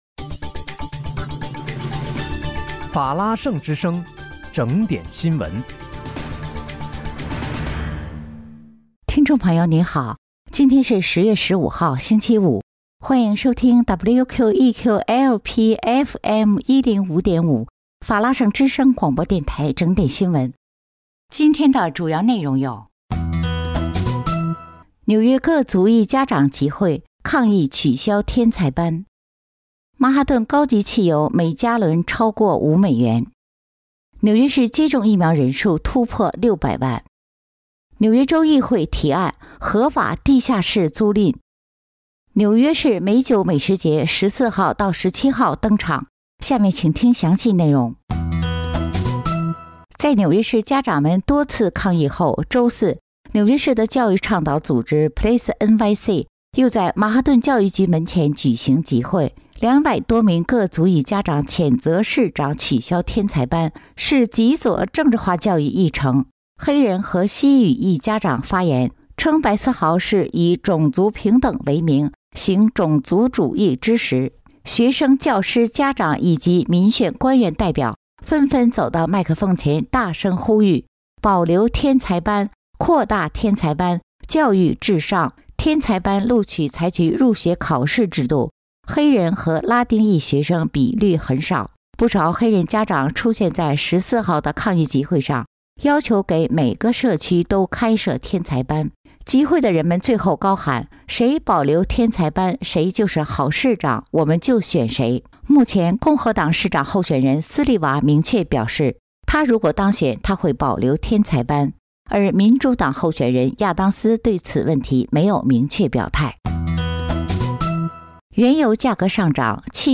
10月15日（星期五）纽约整点新闻